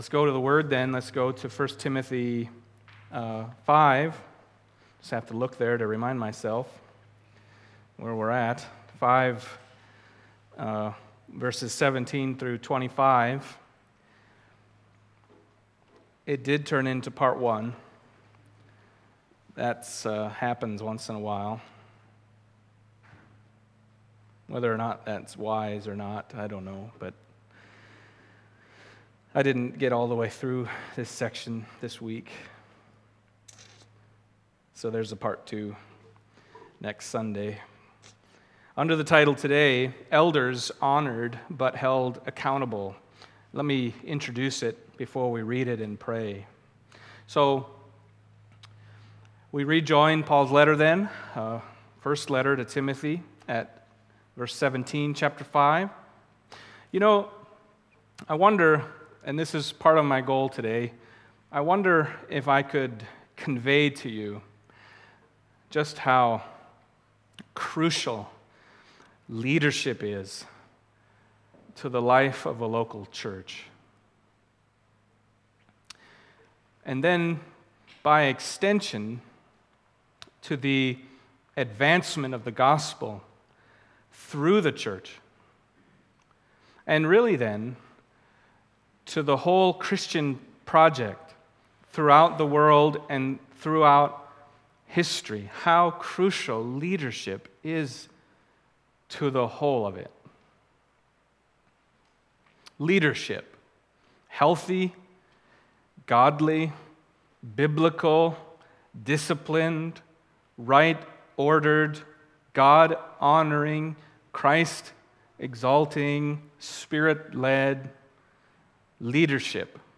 Passage: 1 Timothy 5:17-25 Service Type: Sunday Morning